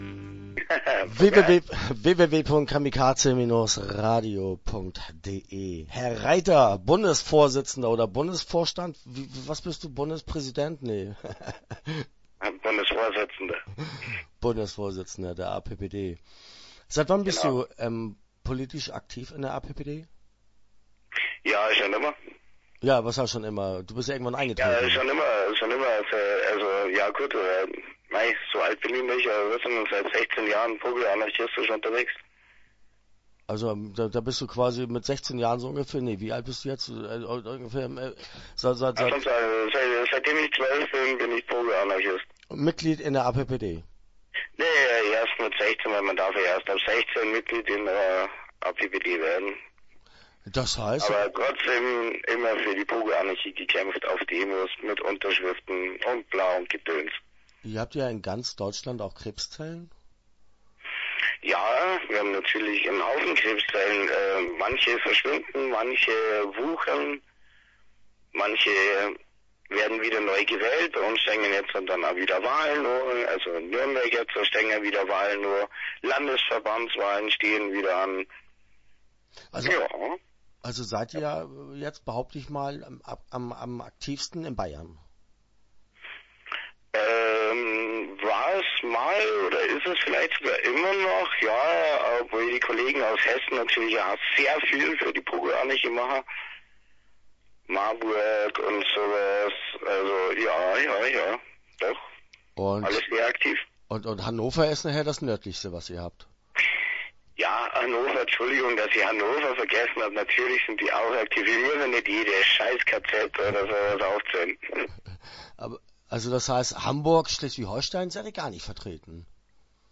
APPD - Interview Teil 1 (10:14)